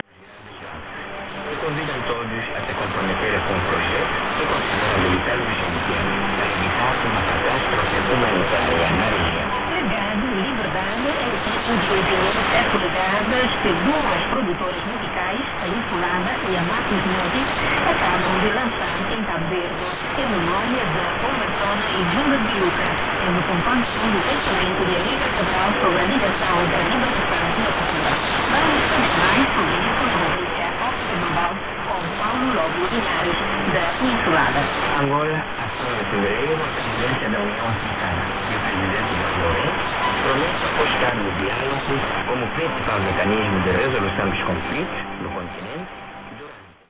RX: LOWE HF-150
antenna: HiQBBA